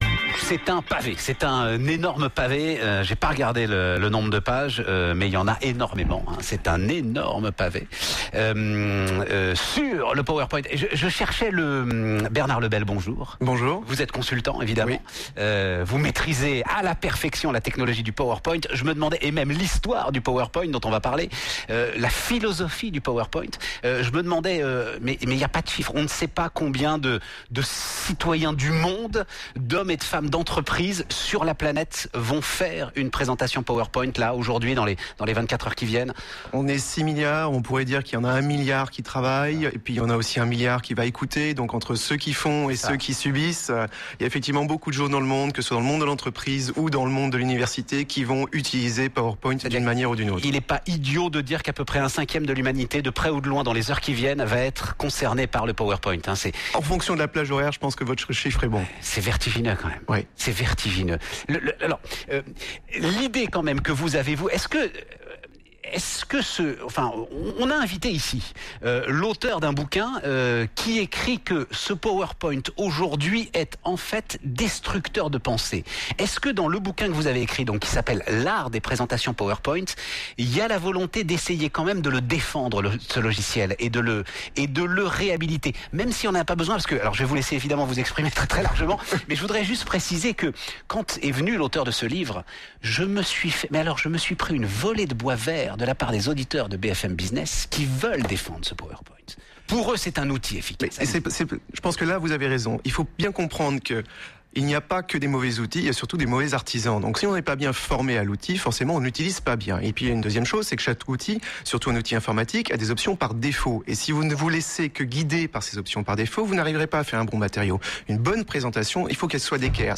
Interview sur BFM par Stéphane Soumier dans Good Morning Business